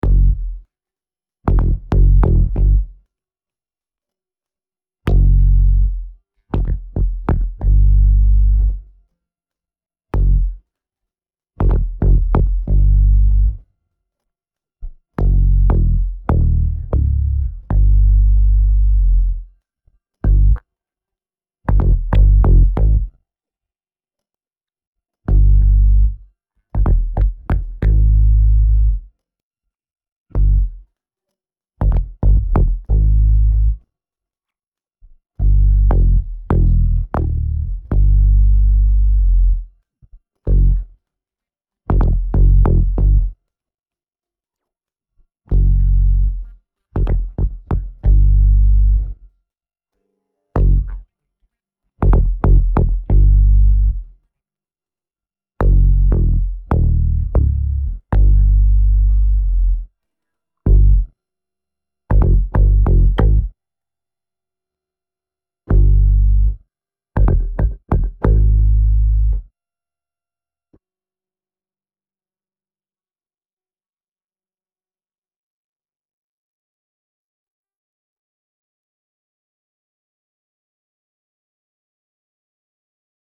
111 BPM